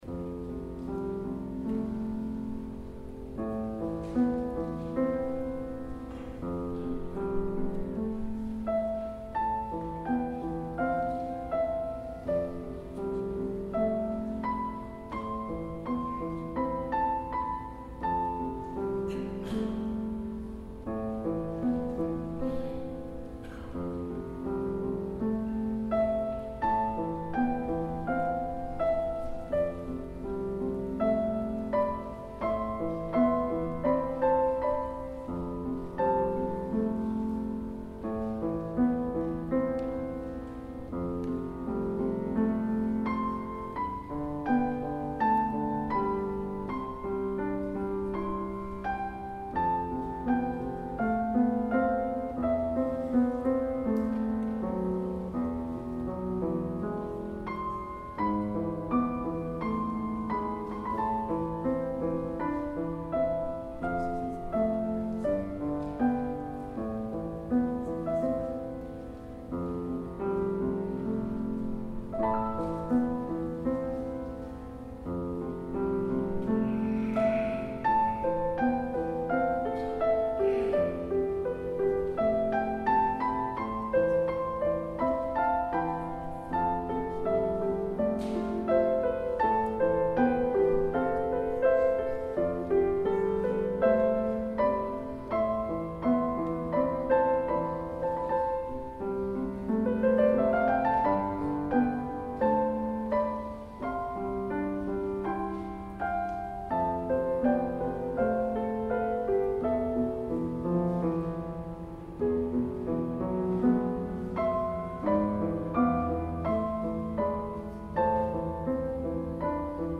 The St. William choir presented a Tenebrae Service on Palm Sunday 2015.
Track 1    - Piano prelude